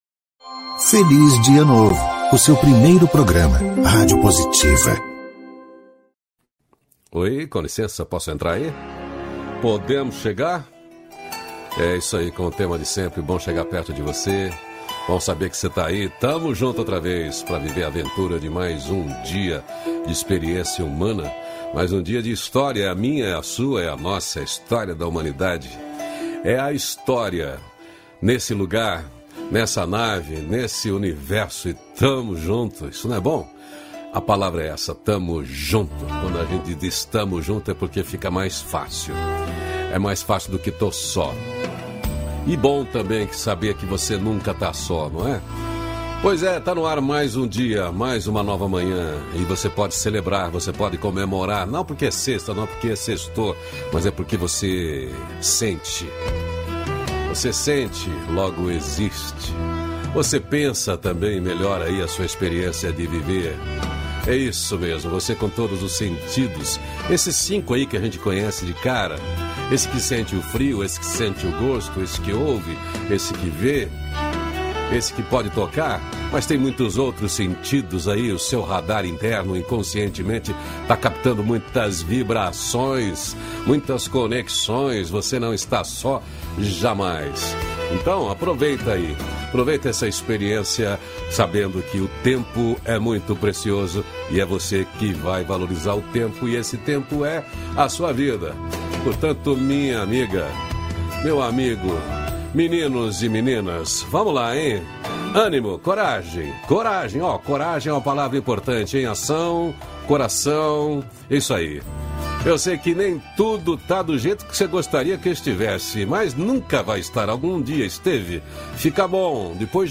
-502FelizDiaNovo-Entrevista.mp3